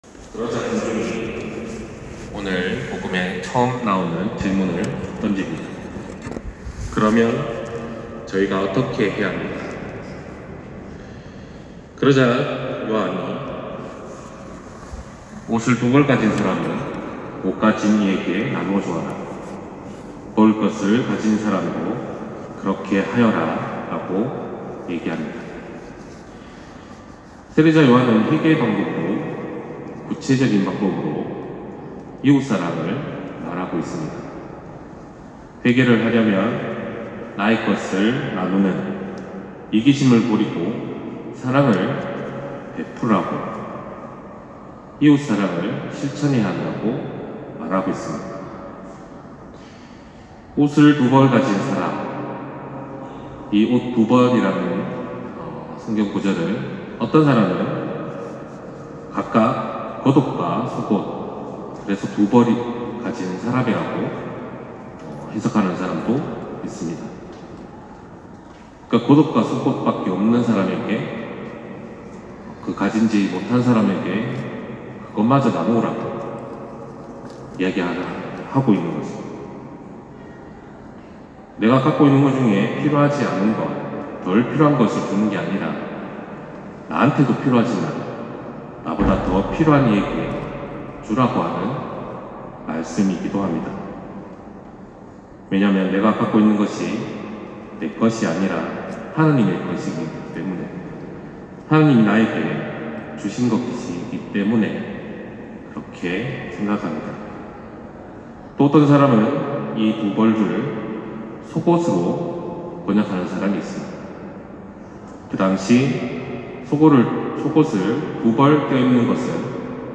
241214 신부님 강론말씀